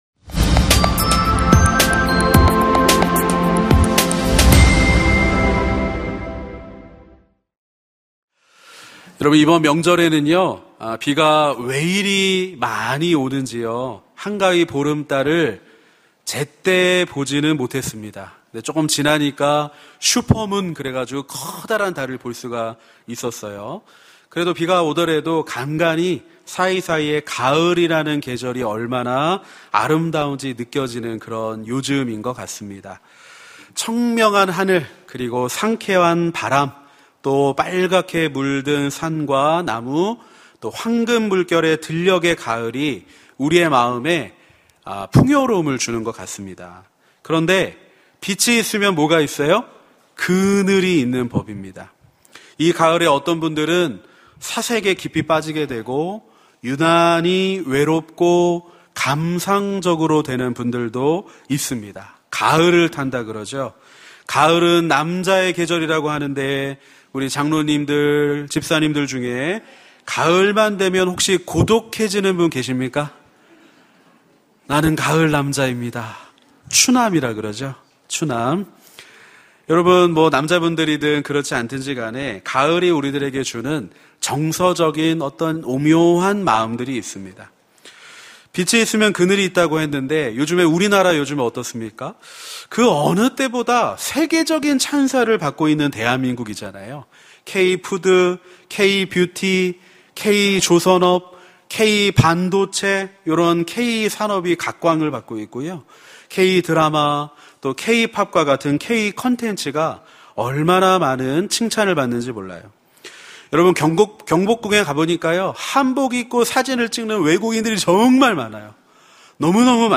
설교 : 시니어예배